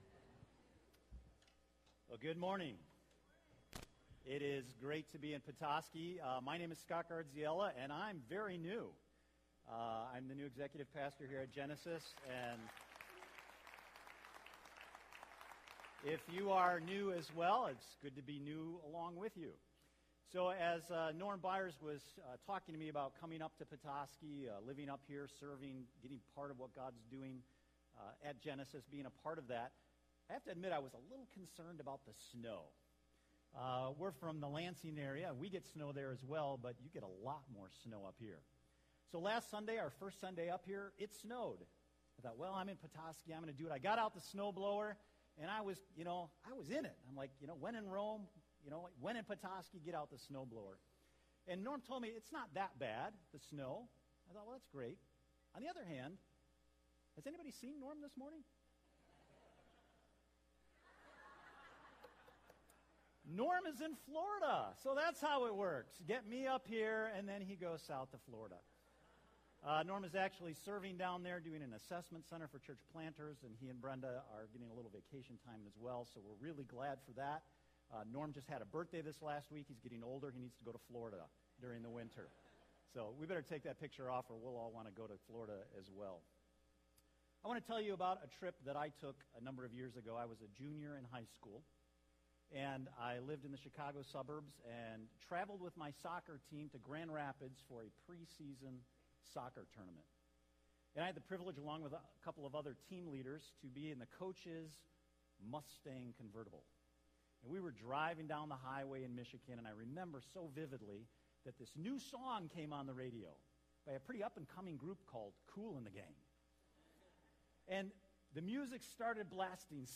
Service Type: Sunday Morning Preacher